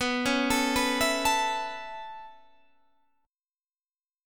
Listen to B7sus2sus4 strummed